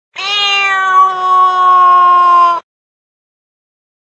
PixelPerfectionCE/assets/minecraft/sounds/mob/cat/hiss3.ogg at ca8d4aeecf25d6a4cc299228cb4a1ef6ff41196e
hiss3.ogg